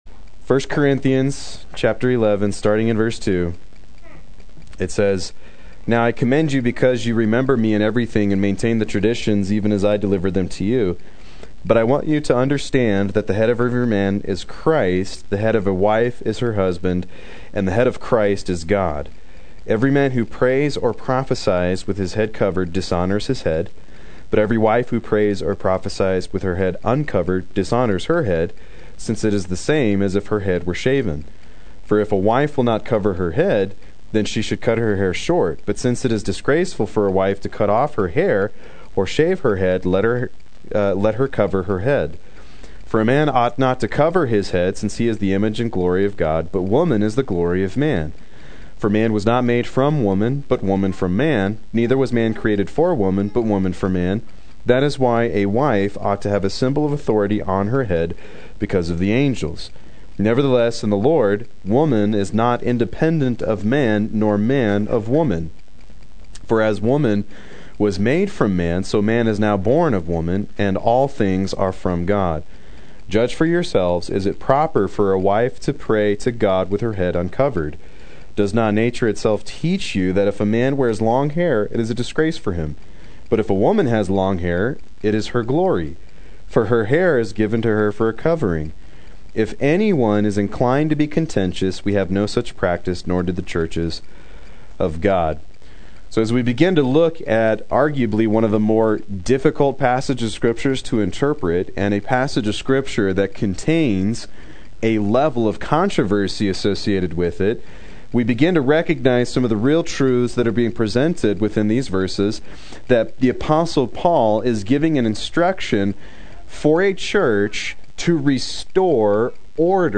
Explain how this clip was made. Proclaim Youth Ministry - 07/29/16